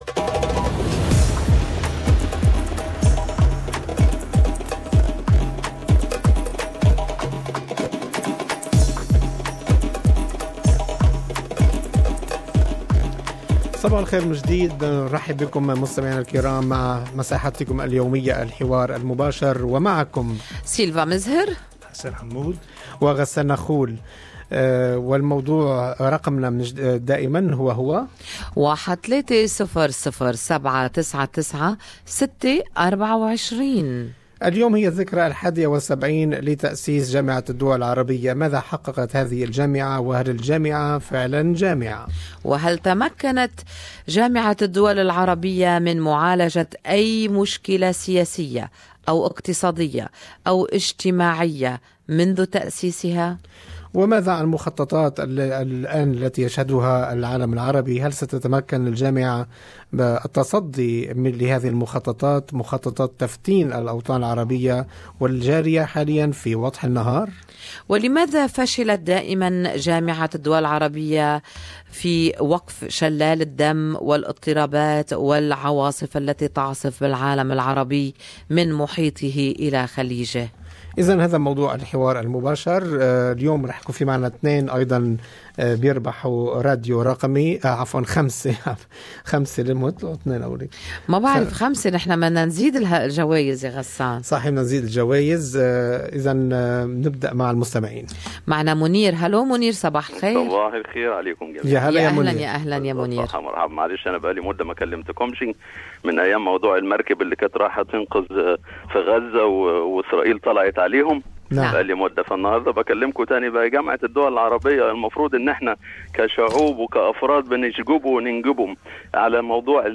Talkback listeners opinions .